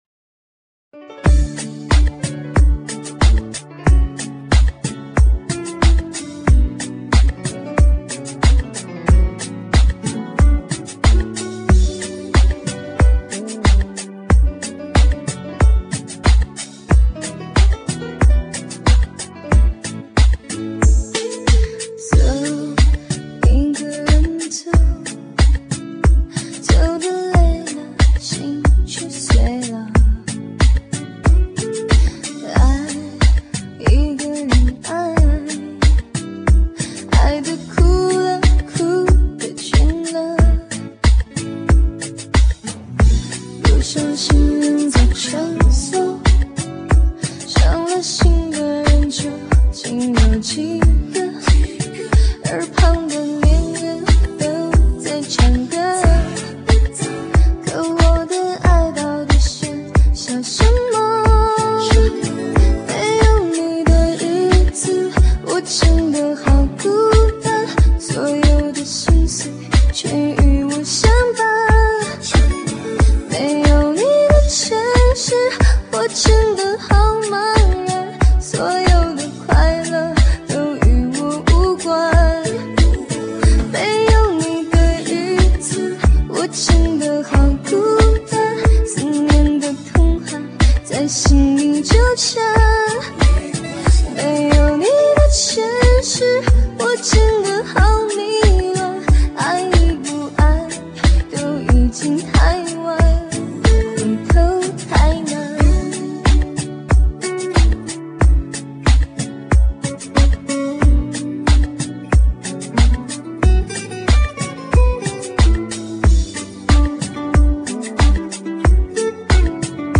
汽车音乐专用 顶级示范天碟
撩人心搏的魅力舞曲
并四